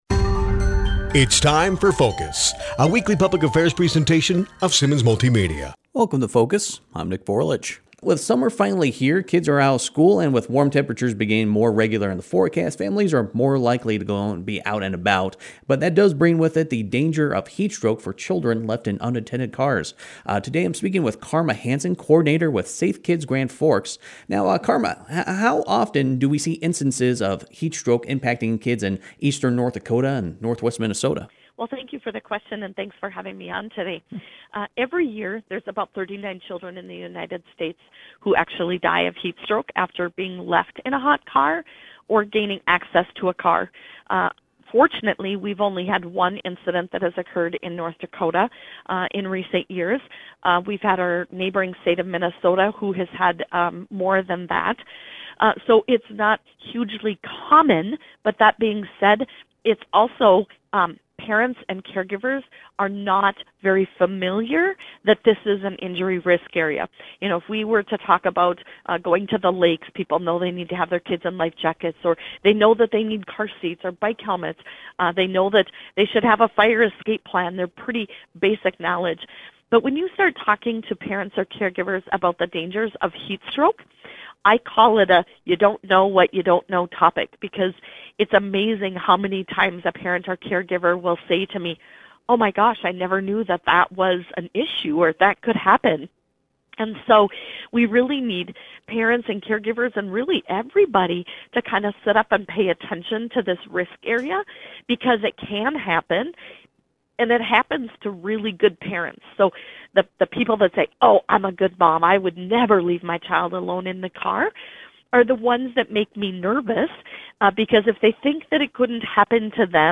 7.7.2024 – Simmons Multimedia Focus Radio Interview – Heatstroke and Kids in Hot Vehicles